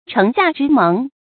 注音：ㄔㄥˊ ㄒㄧㄚˋ ㄓㄧ ㄇㄥˊ
城下之盟的讀法